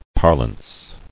[par·lance]